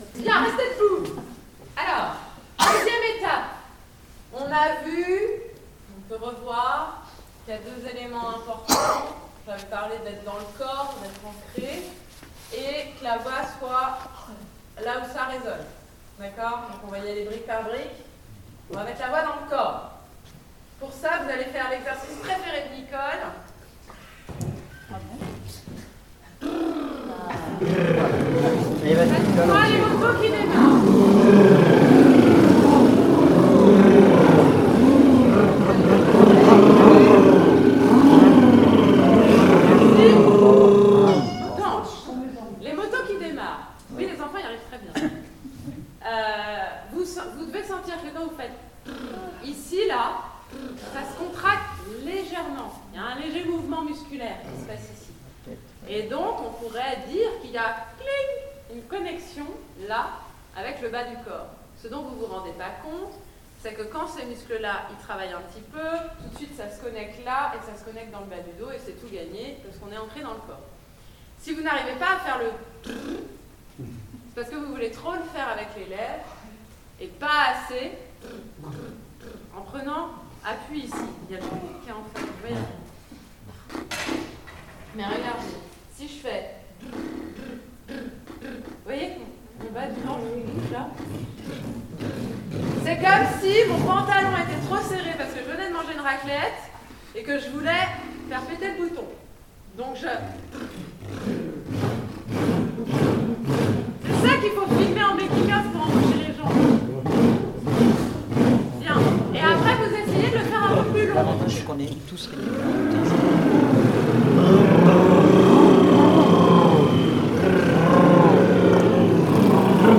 Echauffement vocal
Ces enregistrements au format MP3 ont été réalisés lors de la répétiton du 22 septembre 2025.